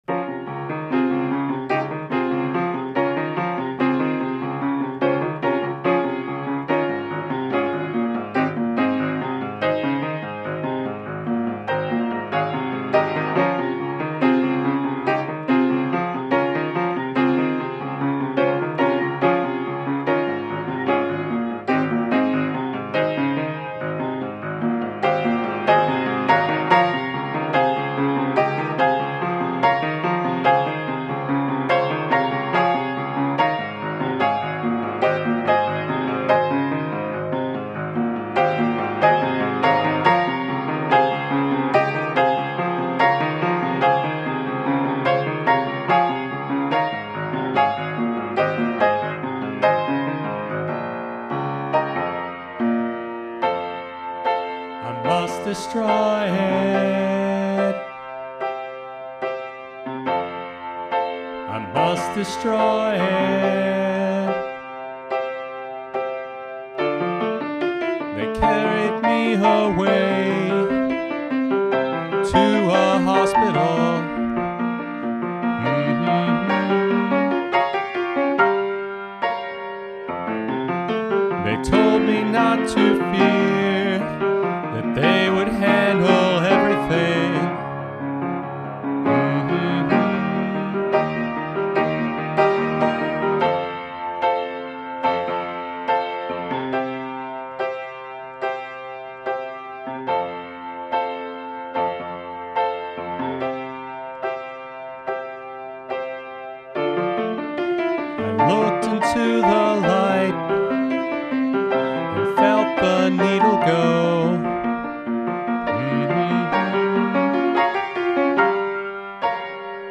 rock opera
piano and voice